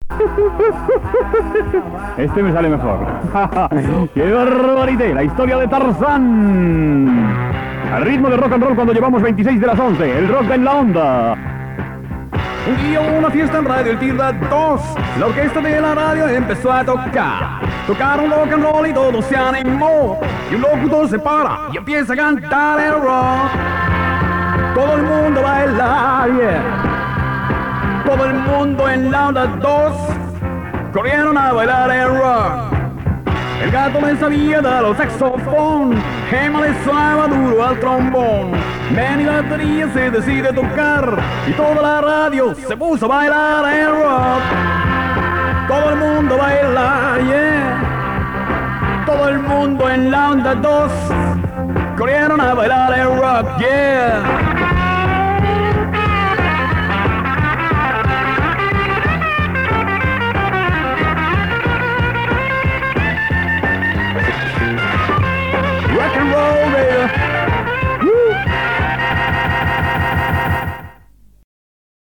Tema musical, hora, cançó del programa
Musical